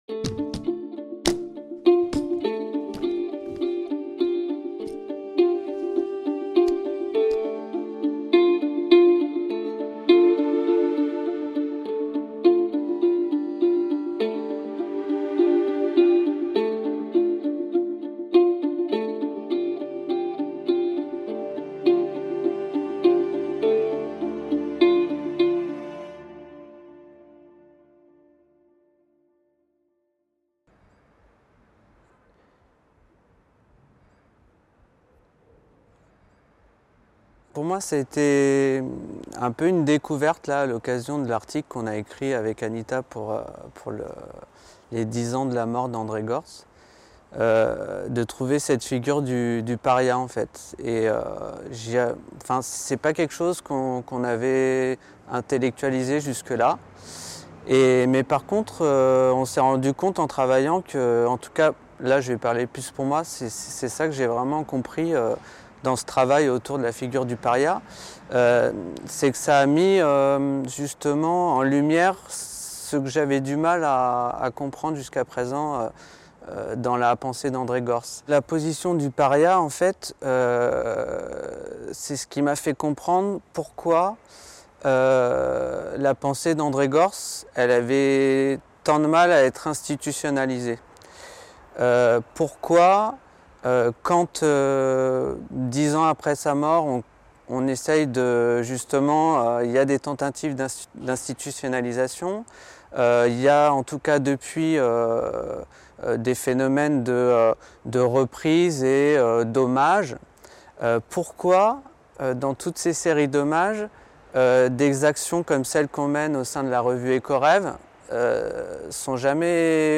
Ce qu'il faut savoir sur la migration en Europe aujourd'hui. Entretien réalisé lors de la table ronde de la chaire "Exil et Migrations" du Collège d'études mondiales (FMSH)